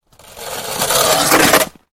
Лёд звуки скачать, слушать онлайн ✔в хорошем качестве